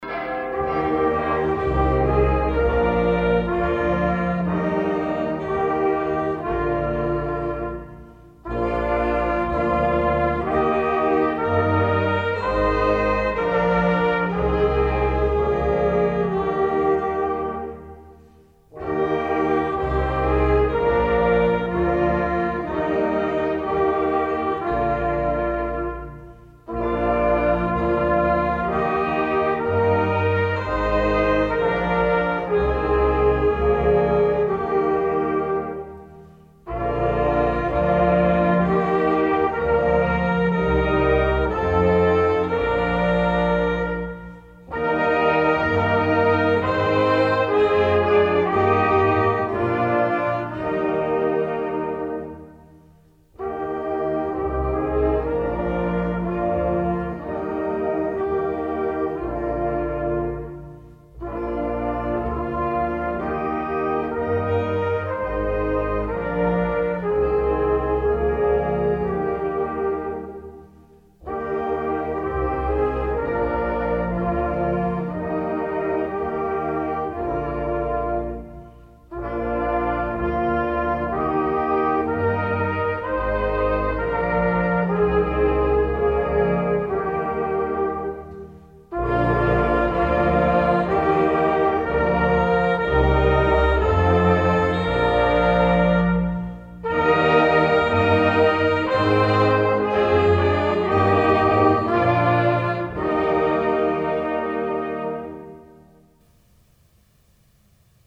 Nach dem Glockenläuten ist nach meiner Erinnerung bei jeder Bestattungsfeier dieser Choral angestimmt worden.